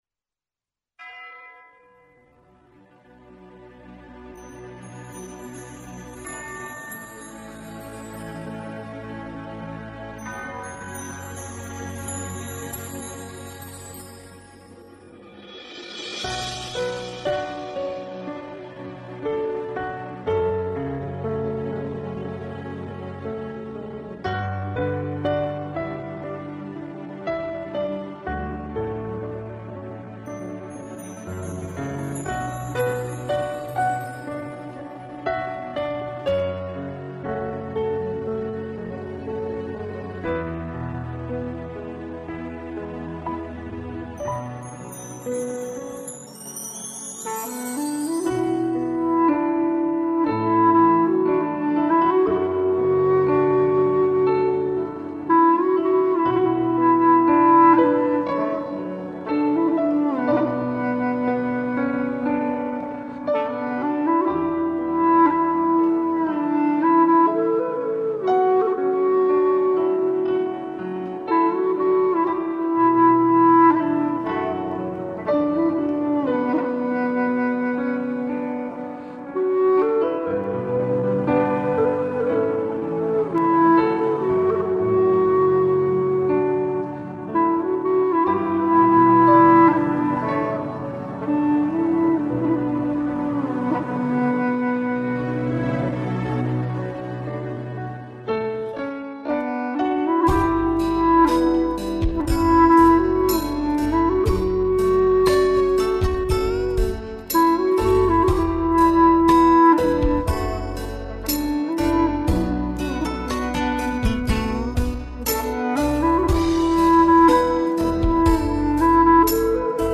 调式 : D调 曲类 : 独奏